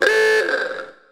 roadster_horn.ogg